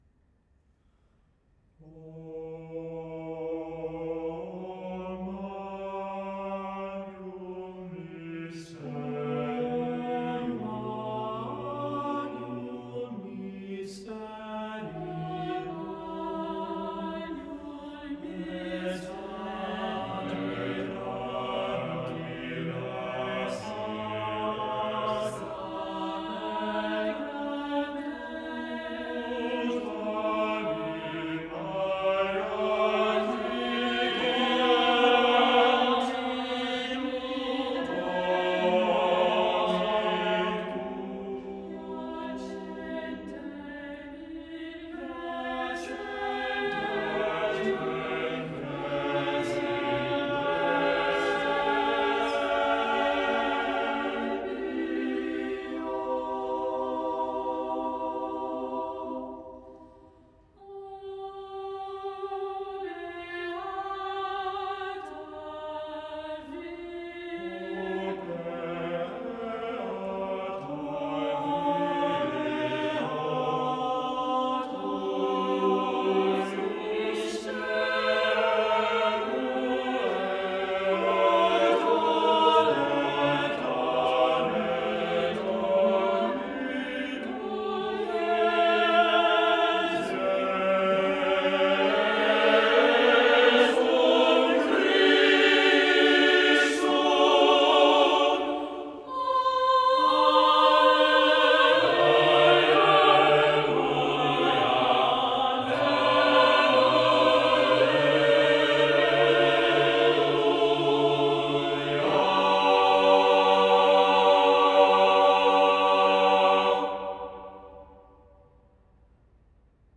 Motet